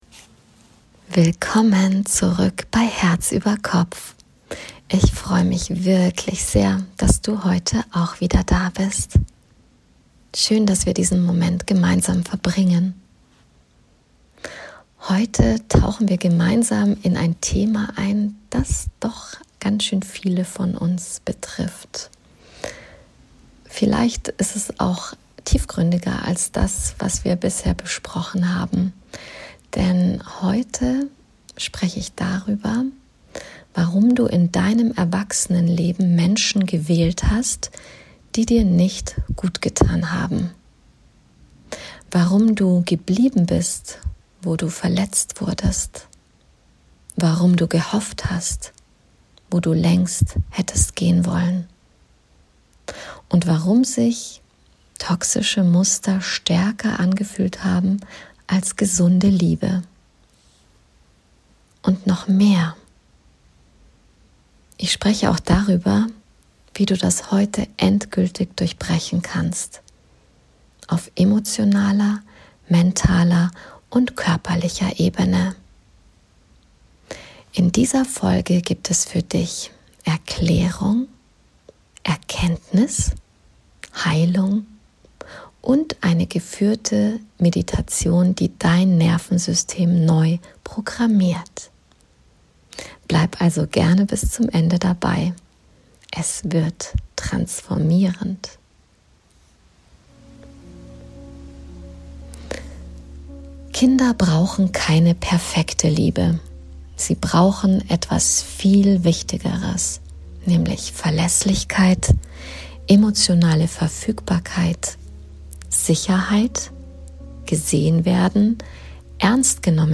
eine geführte Innere-Kind-Heilmeditation